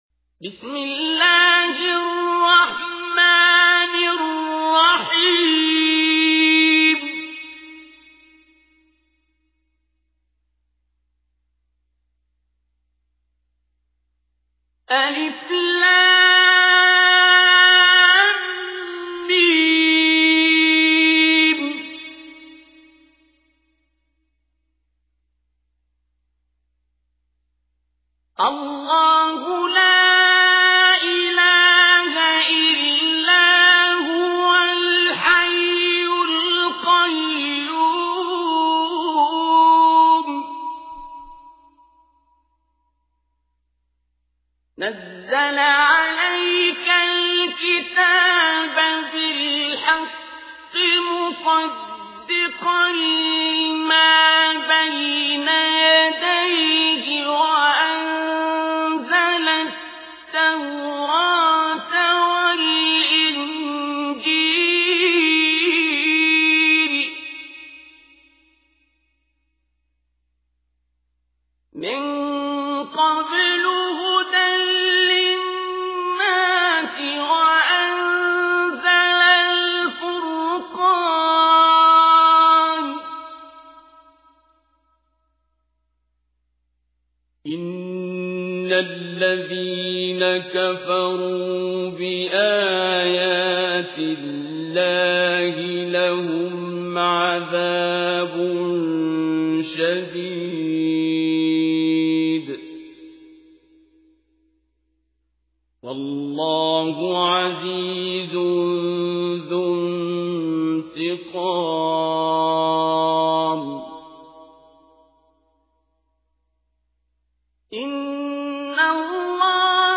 تحميل : 3. سورة آل عمران / القارئ عبد الباسط عبد الصمد / القرآن الكريم / موقع يا حسين